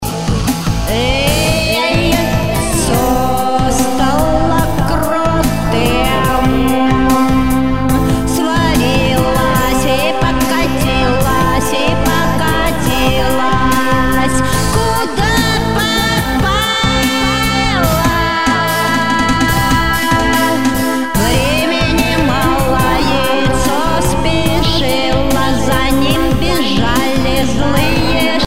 • Жанр: Арт-рок